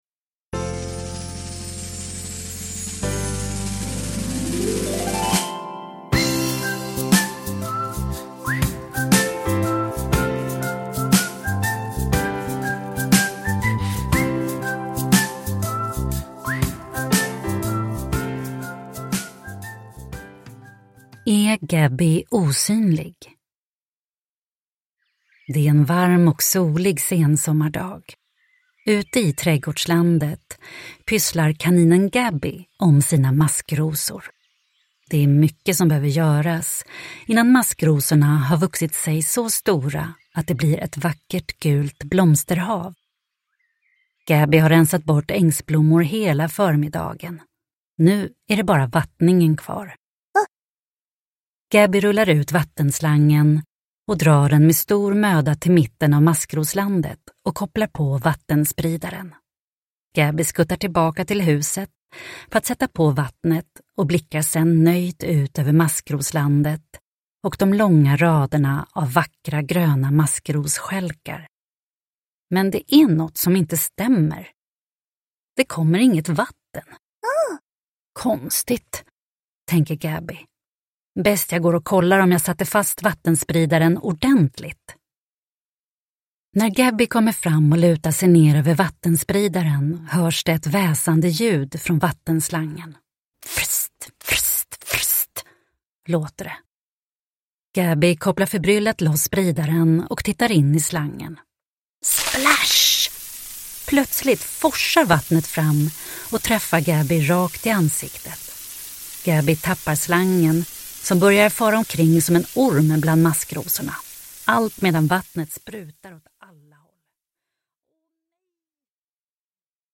Är Gabby osynlig? – Ljudbok – Laddas ner